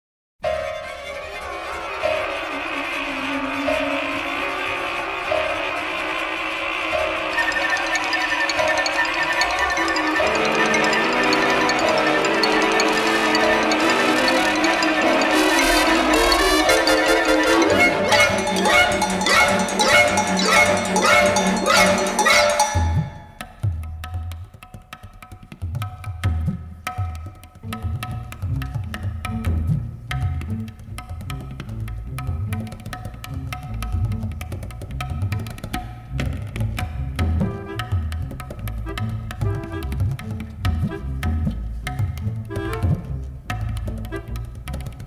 remixed and mastered from the original 1/2" stereo tapes.